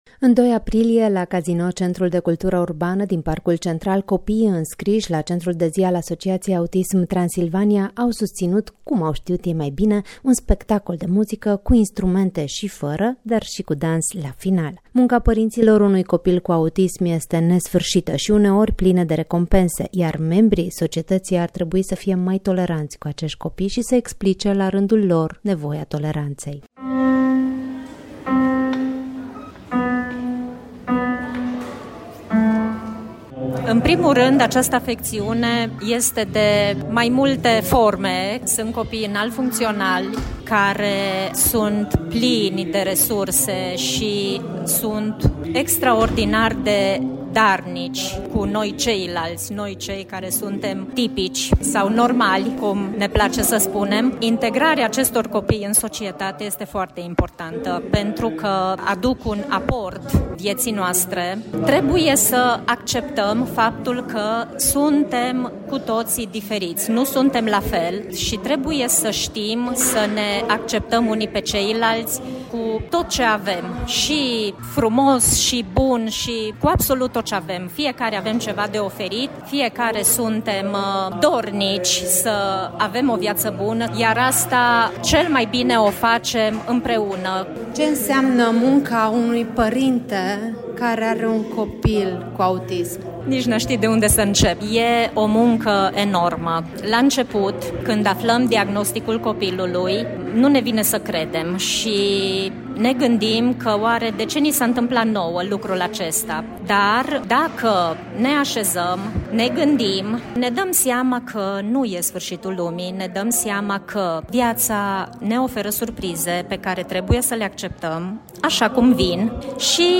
În 2 aprilie, la Casino Centrul de Cultură Urbană din Parcul Central, copiii înscriși la Centrul de zi al Asociației Autism Transilvania au susținut, cum au știut ei mai bine, un spectacol de muzică, cu instrumente și fără, dar și cu dans la final.
reportaj-autism.mp3